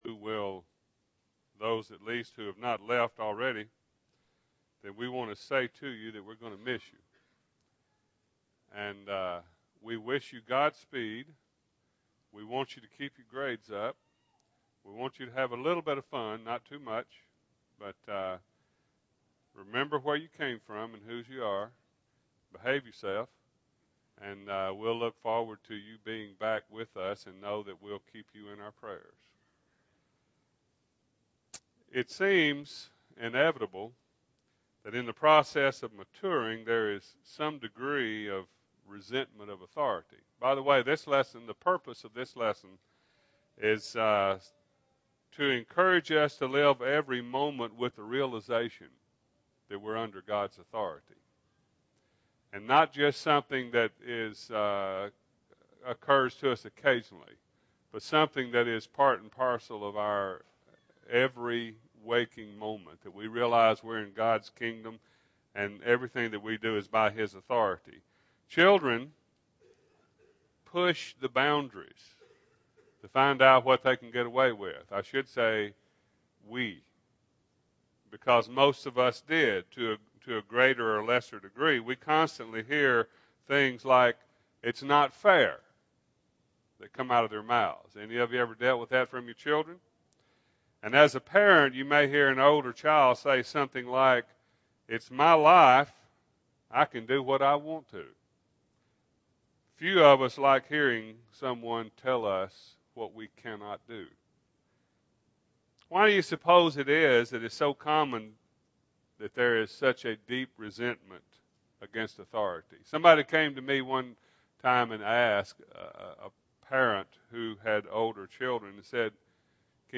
Authority – Bible Lesson Recording